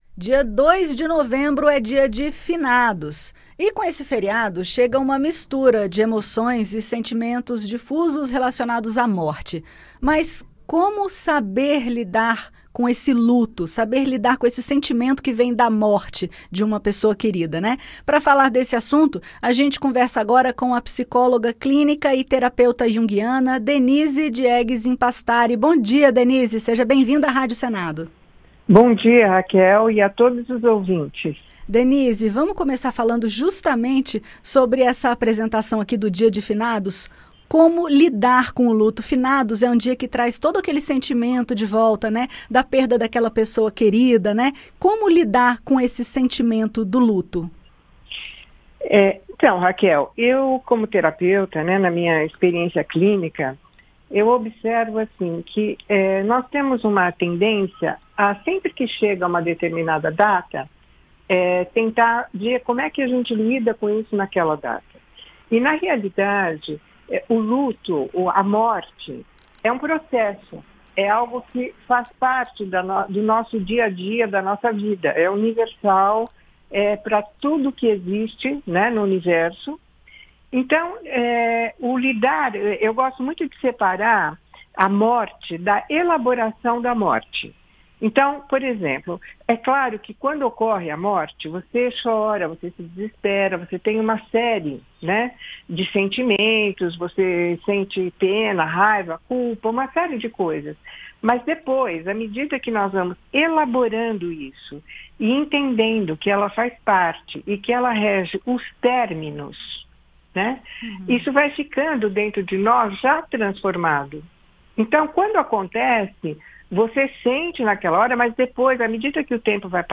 Psicóloga fala sobre processo de luto e como encará-lo